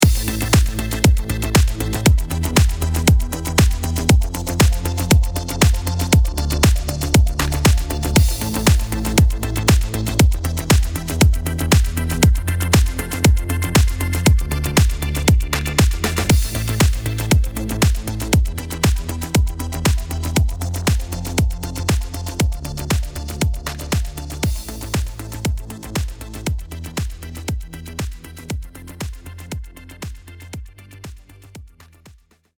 In this tutorial, however, we’ll do kind of the opposite, and treat a modern sounding drum track with some carefully adjusted compression – so that it sounds polished and radio-ready in the end!
All right, now let’s hear what our song sounds like with the SDC engaged: